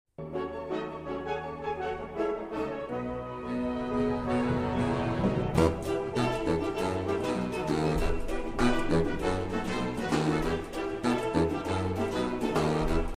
Neulich in der Orchesterprobe💃🏼🕺🏼 .